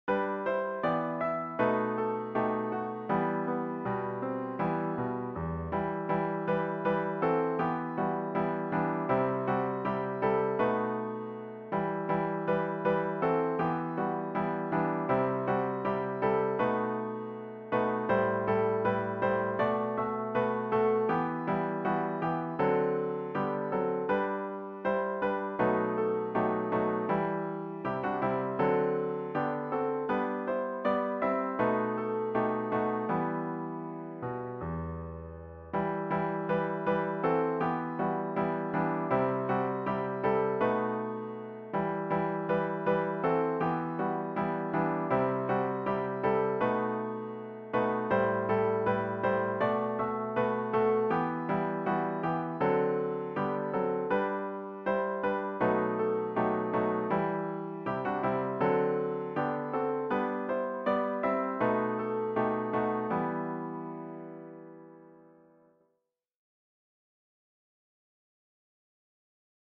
A capella arrangements for mixed chorus.